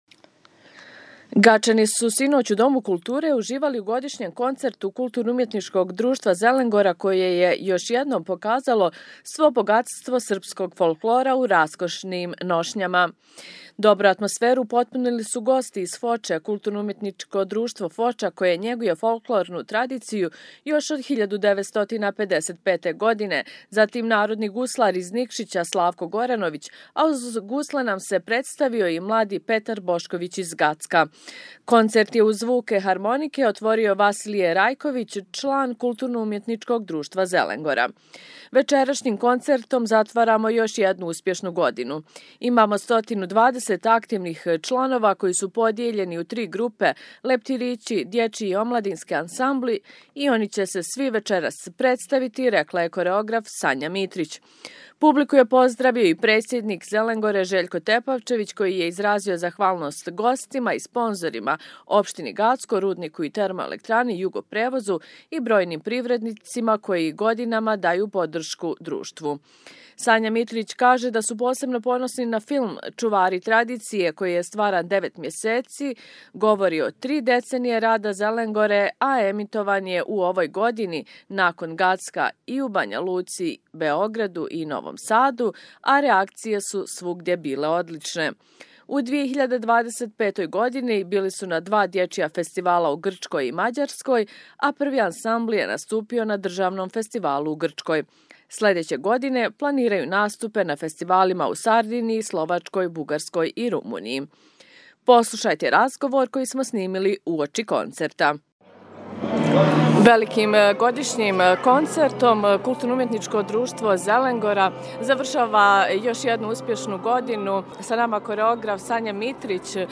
Gačani su sinoć u Domu kulture uživali u godišnjem koncertu KUD-a „Zelengora“ koji je još jednom pokazalo svo bogatstvo srpskog folklora u raskošnim nošnjama.